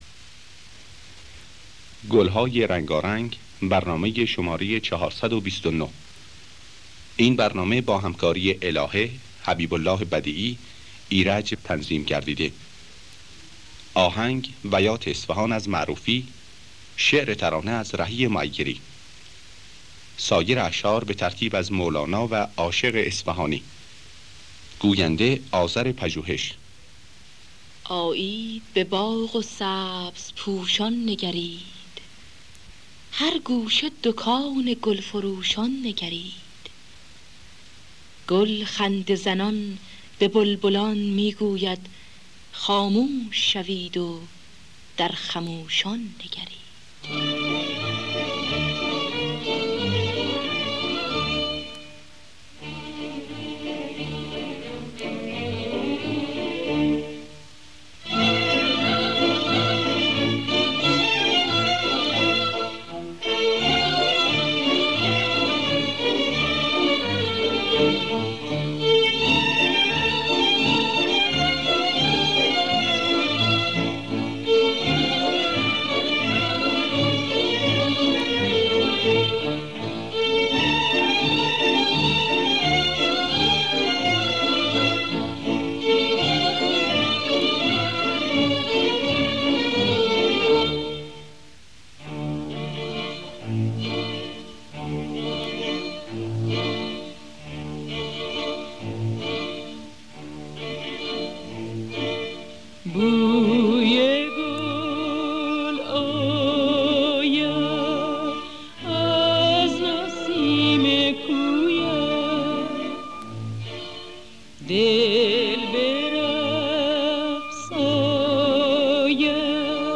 گلهای رنگارنگ ۴۲۹ - بیات اصفهان